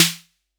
Tr8 Snare 01.wav